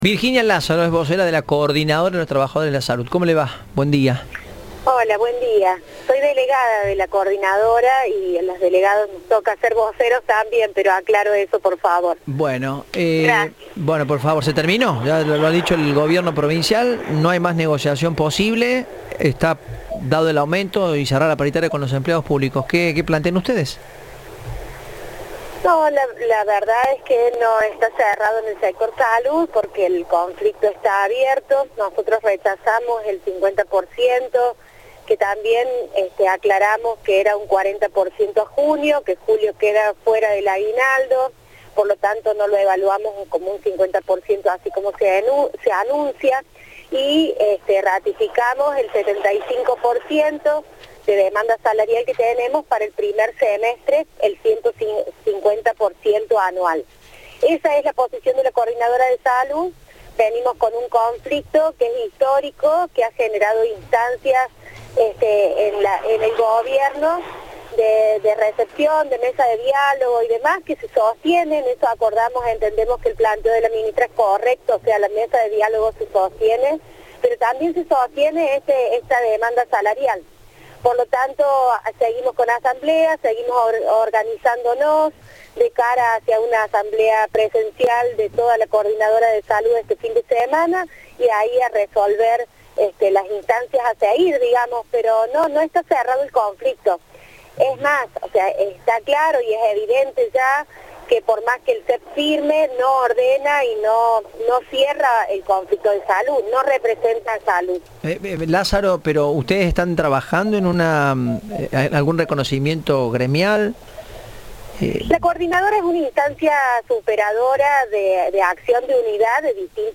Gabriela Barbás, ministra de Salud de la Provincia, dijo a Cadena 3: “El sector Salud está dentro de lo que son las paritarias, que ayer firmó el SEP (70% de aumento).